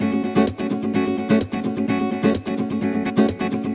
VEH3 Nylon Guitar Kit 1 - 20 F# min.wav